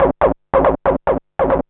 SNTHBASS060_DANCE_140_A_SC3.wav
1 channel